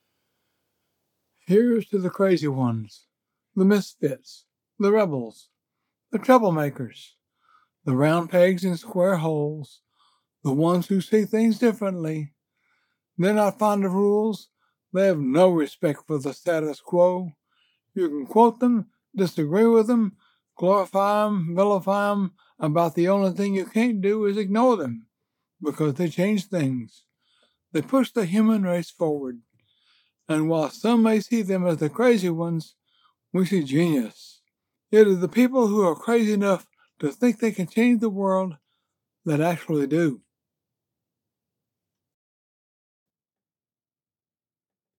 American Southern senior citizen looking to voice some projects fit for him
The Crazy Ones, an ad for Apple
English - Southern U.S. English